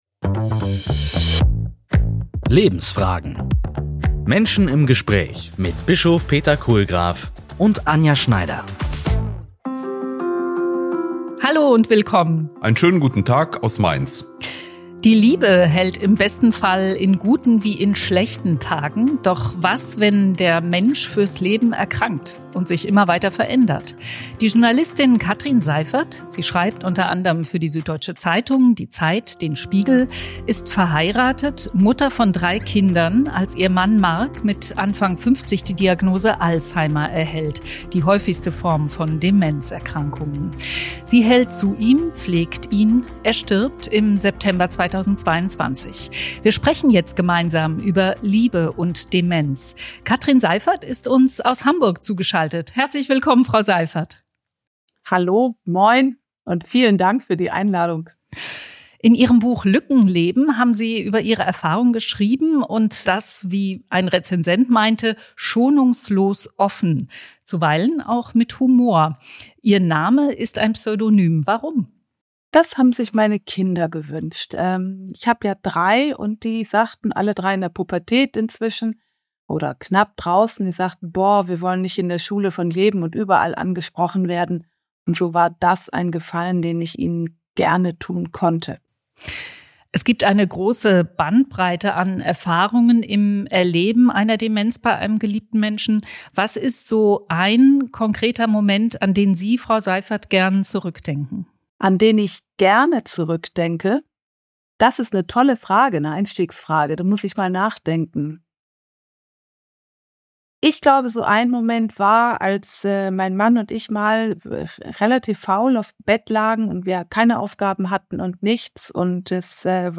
Lebensfragen – Menschen im Gespräch